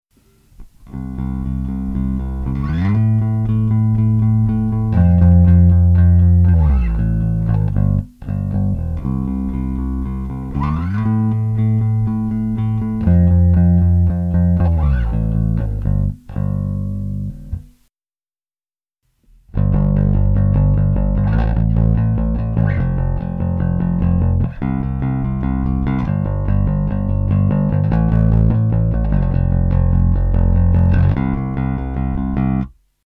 Во вложении небольшой пример бас гитары на разных настройках.